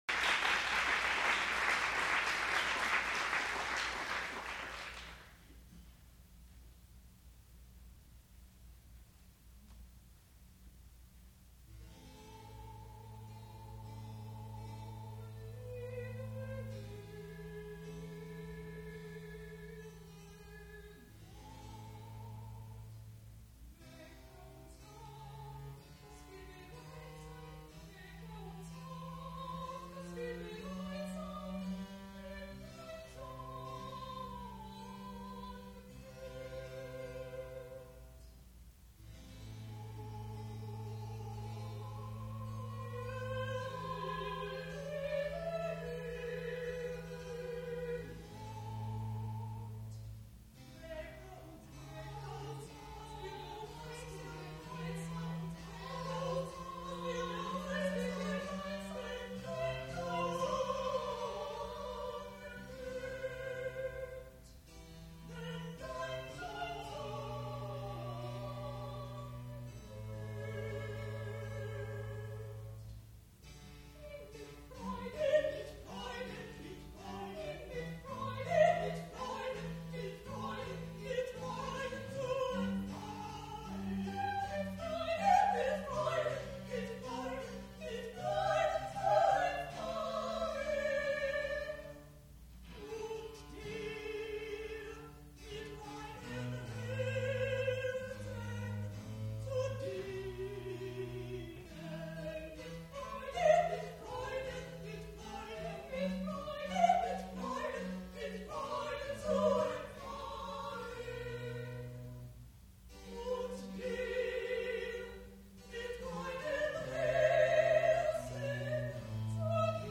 sound recording-musical
classical music
mezzo-soprano
piano
viola da gamba
Graduate Recital